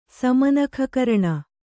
sama nakha karaṇa